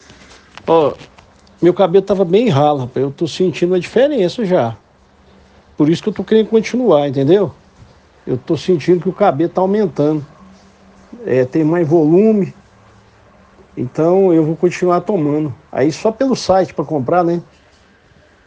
Cliente-Harmony-Men.ogg